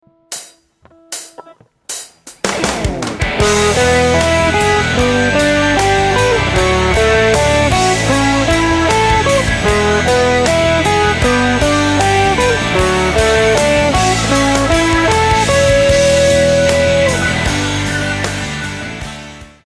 In this guitar lesson the CAGED Chord System will be used over a 4 chord progression showing ideas to create a guitar solo based on arpeggios.
The chords in the example are E G D and A major which are the same chords used in the chorus of Alive by Pearl Jam and countless other songs.
The next exercise uses the C or D major shape.
CAGED C/D Chord Shape Arpeggios